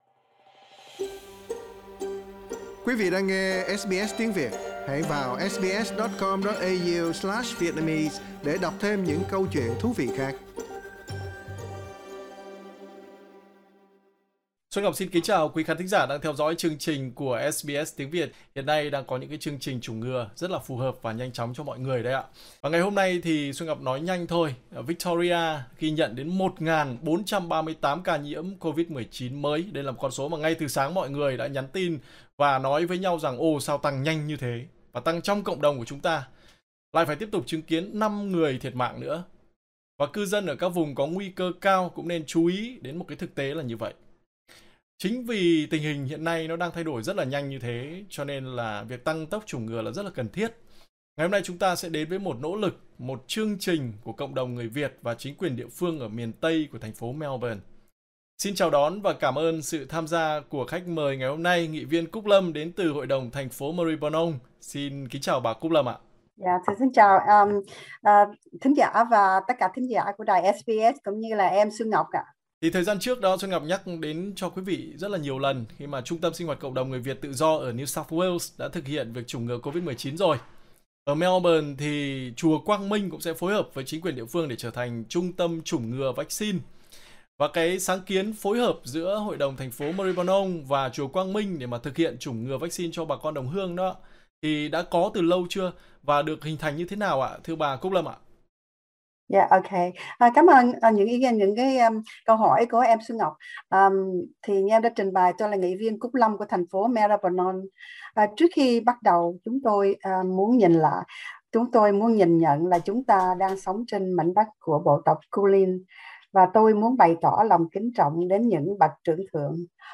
Trả lời phỏng vấn của SBS Tiếng Việt, nghị viên thành phố Maribyrnong, bà Cúc Lâm cho hay, sáng kiến phối hợp giữa Hội đồng thành phố Maribyrnong, Cohealth, và chùa Quang Minh đã được bàn thảo từ lâu, nhằm thực hiện chủng ngừa vắc-xin cho bà con đồng hương, tăng tốc độ chủng ngừa toàn tiểu bang.